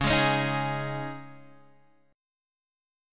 Dbsus2 chord